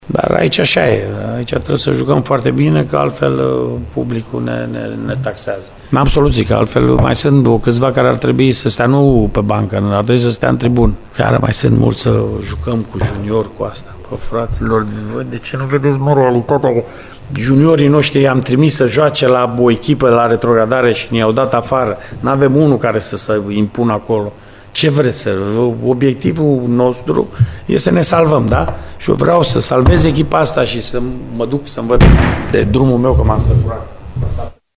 Tehnicianul arădenilor a părăsit nervos conferința de presă de după înfrângerea cu 2-1, de acasă, cu Sepsi Sfântu Gheorghe: